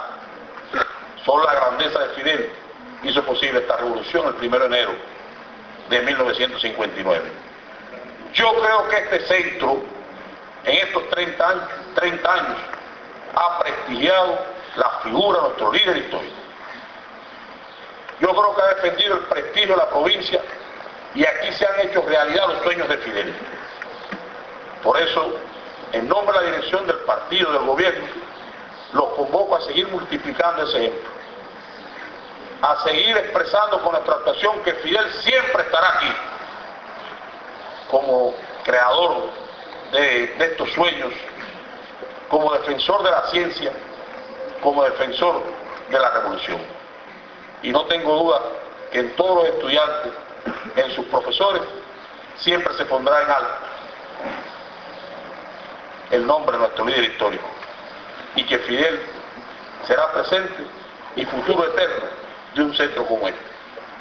La conmemoración, presidida por la imagen del Líder Histórico de la Revolución Cubana y Bandera Cubana y del 26 Julio, devino ocasión idónea para entregar reconocimientos a siete fundadores del centro, a educadores  jubilados que se reincorporaron a este y a estudiantes destacados.
Palabras-de-Federico-Hern--ndez.mp3